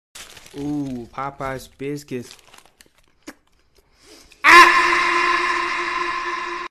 ooo popeyes biscuits Tik Tok Sound Effect - MP3 Download